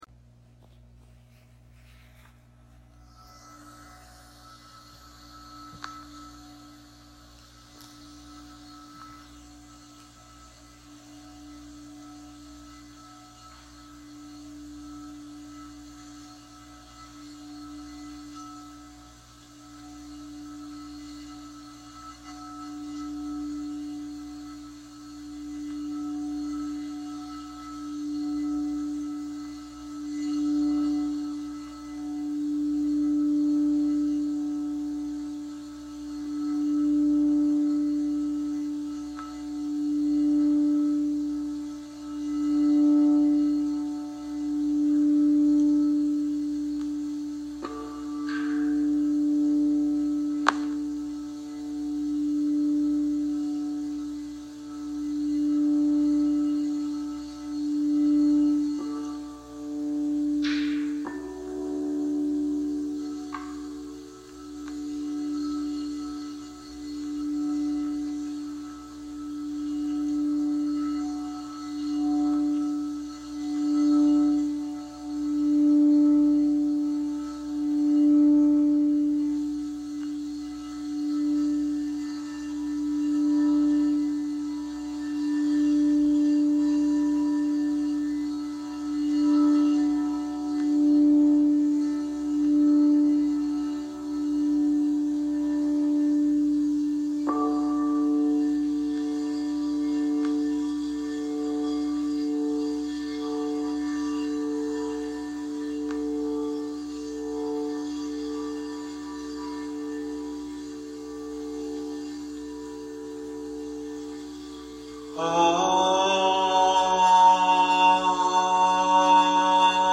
Crystal Singing Bowl Chakra Meditation
It is a musical voyage that brings the listener into a state of deep relaxation and balance, while harmonizing the Sacral chakra of the body with the soothing tones of crystal singing bowls and vocal toning.